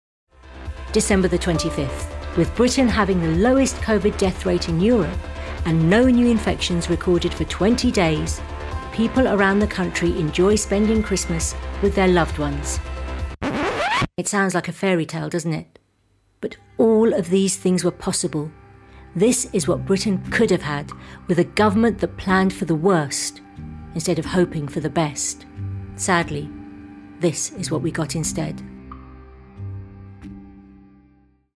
RP + British Indian (Hindi, Punjabi). Friendly, warm, reassuring, youthful, approachable, natural | Rhubarb Voices